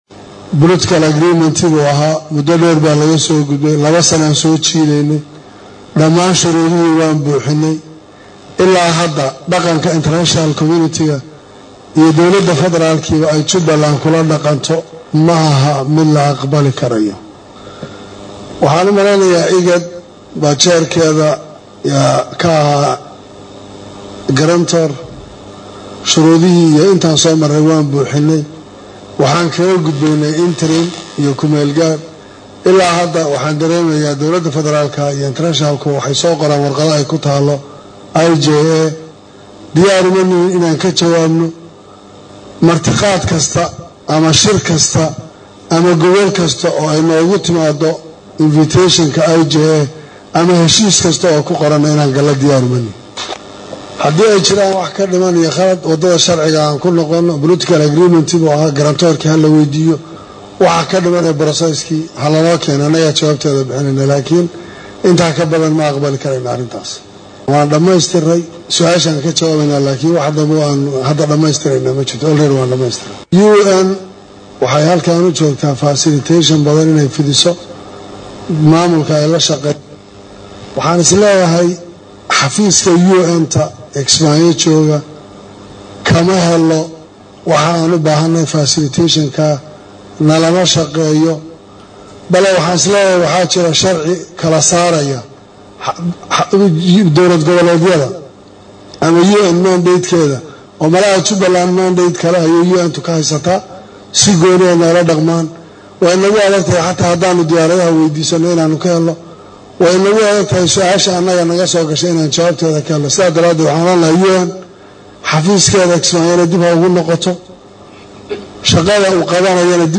Kismaayo(INO)- Madaxweynaha Maamulka Jubbaland Axmed Maxamed Islaam “ Axmed Madoobe” oo Warbaahinta la hadlay ayaa si adag u eedeeyay Dawlada Federaalka Soomaaliya iyo beesha Caalamka.